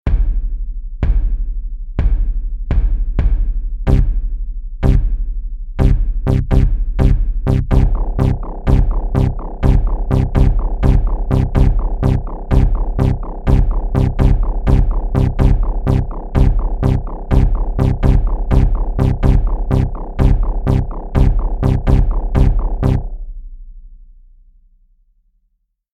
Tady jsem totéž nahrál ve FL Studiu:
Nějak tomu chybí šťáva, že?